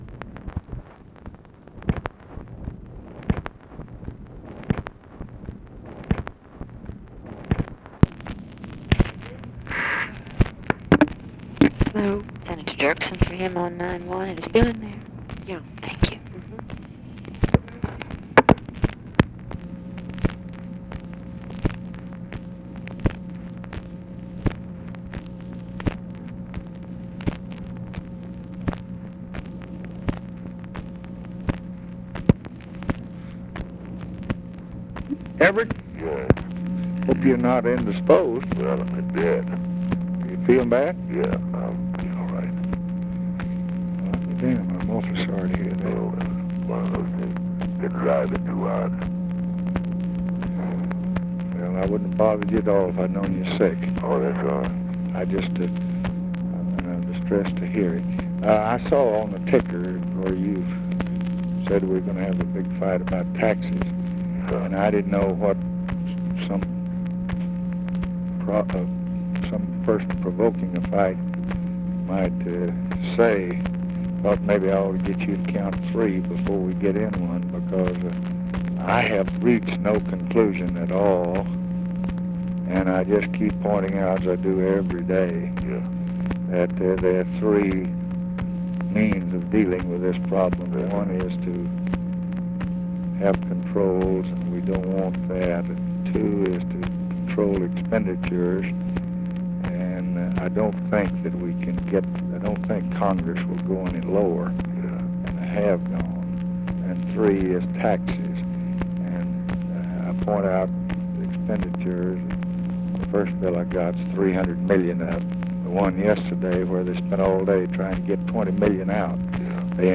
Conversation with EVERETT DIRKSEN, March 30, 1966
Secret White House Tapes